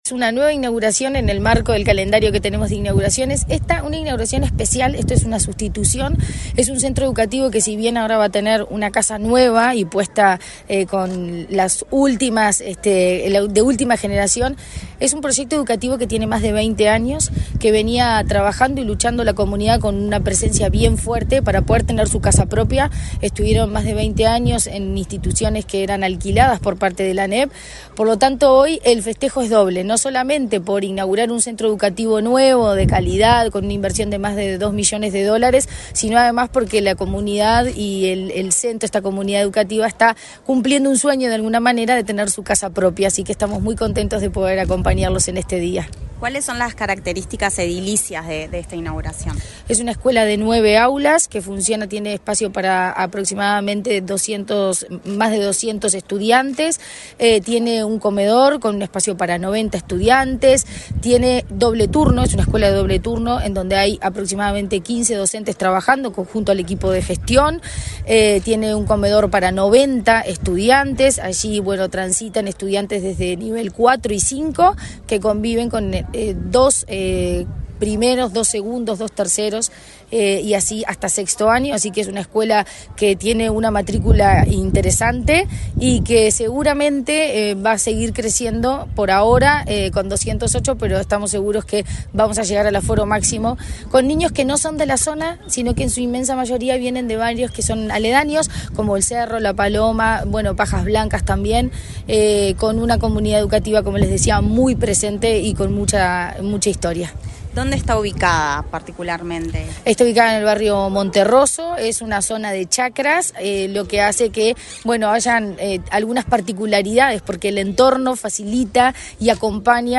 Declaraciones de la presidenta de ANEP, Virginia Cáceres